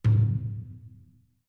SubImpactsHollow FS049001
Sub Impacts, Hollow; Hollow Thud Impact On Metal Drum With Reverb. - Fight Sweetener